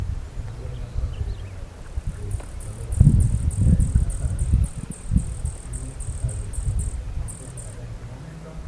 Picaflor Bronceado (Hylocharis chrysura)
Nombre en inglés: Gilded Sapphire
Fase de la vida: Adulto
Localidad o área protegida: Parque Costero del Sur
Condición: Silvestre
Certeza: Observada, Vocalización Grabada